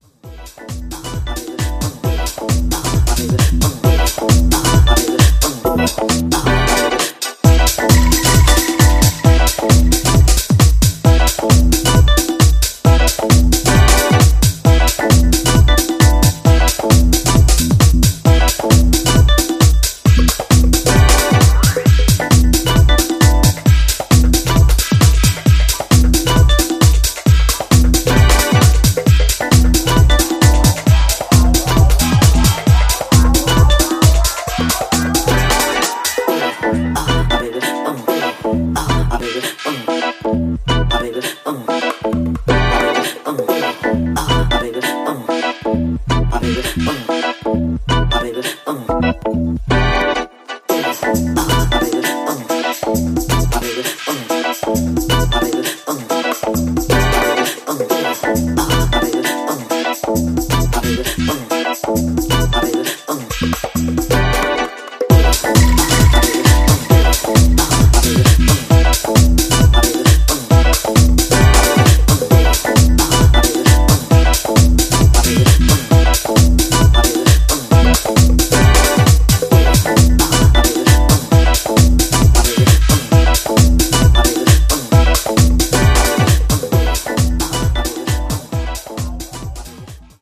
小気味良いパーカッションと共にバウンスするファンキー・テック・ハウス